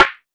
SNARE.18.NEPT.wav